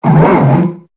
Cri de Judokrak dans Pokémon Épée et Bouclier.